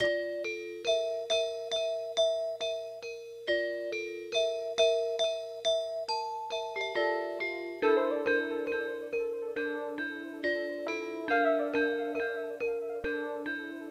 taste of diamonds 66bpm.wav